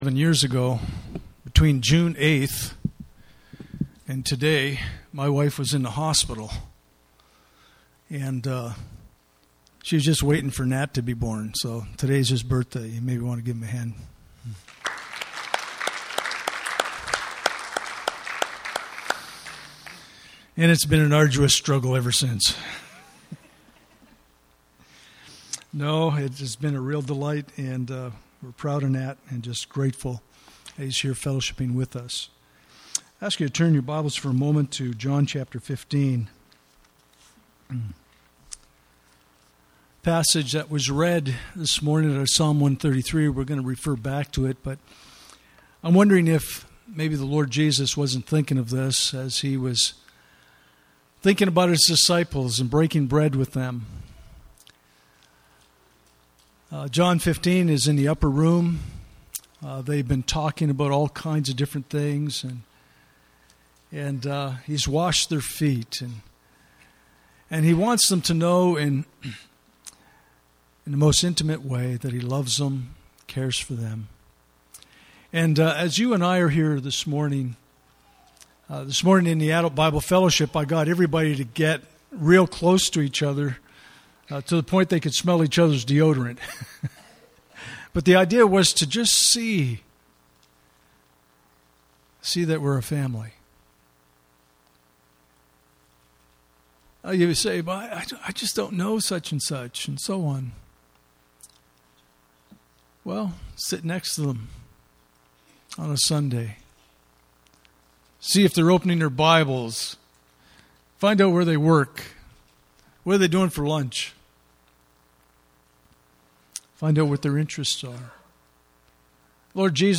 Communion Service
Communion Passage: Psalm 133:1-3 Service Type: Sunday Morning « Are You Enduring Hardships in Life?